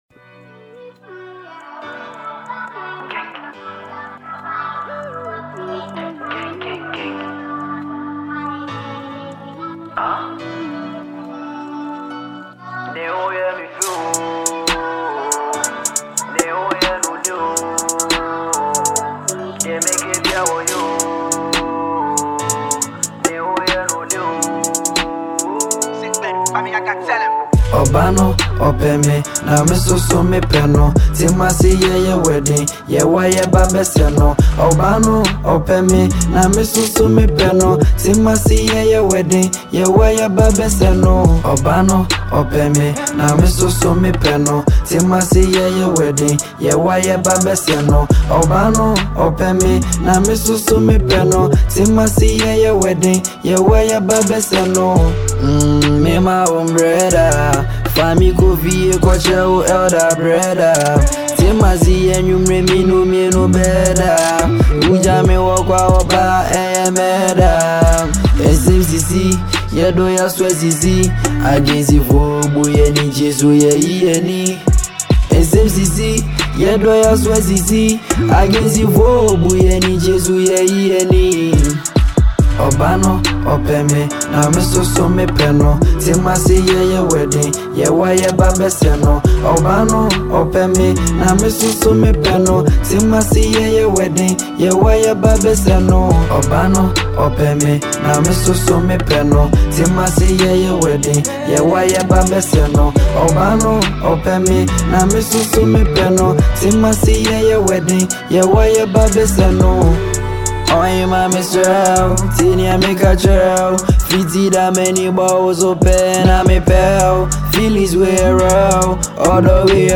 a Ghanaian asakaa rapper
This is a banger all day.